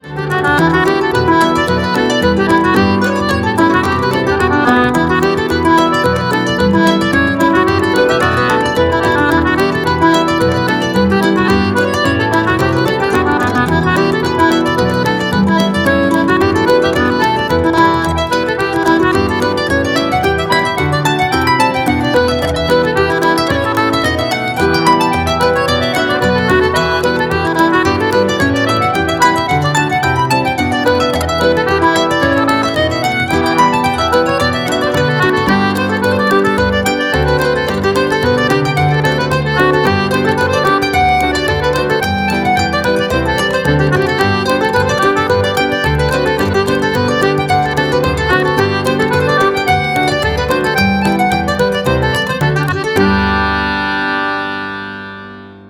piano and arrangements
fiddle & harp
concertina
Violin
Cello
Percussion
Reels